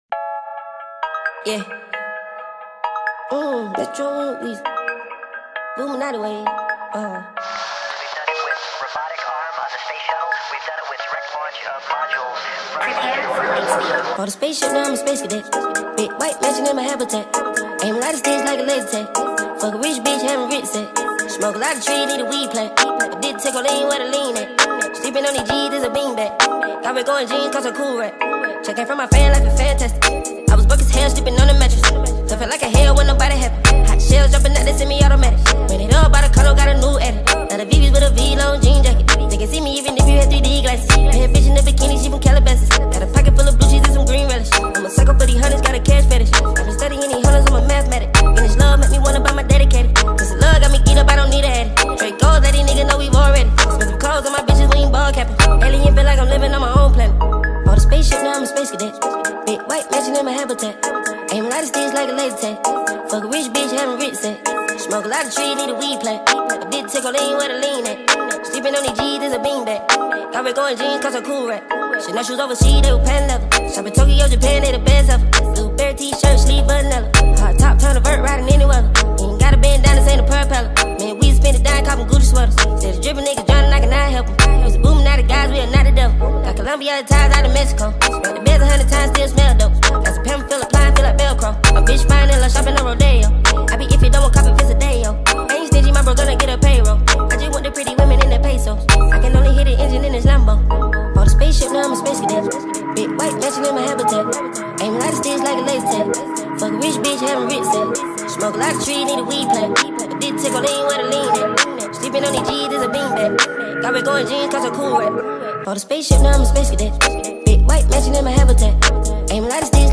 با ریتمی سریع شده